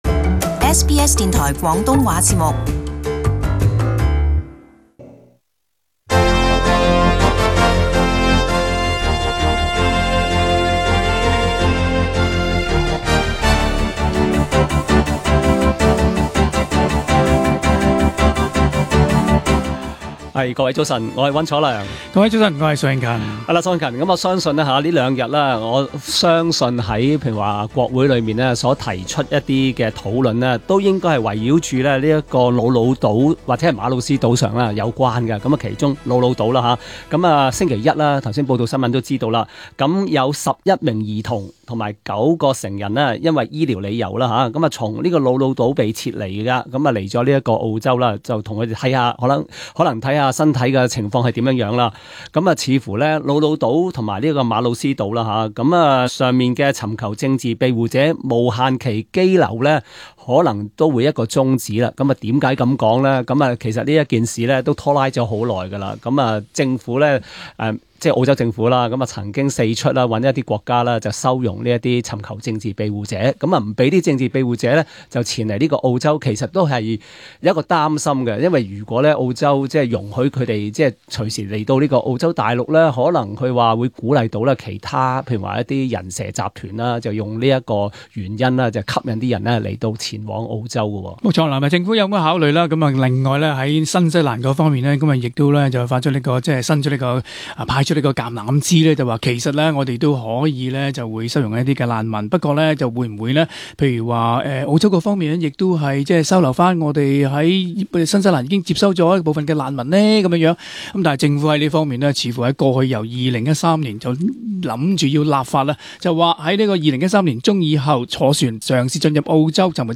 【時事報導】 工黨綠黨表示願意就安置難民考慮支持具爭議性議案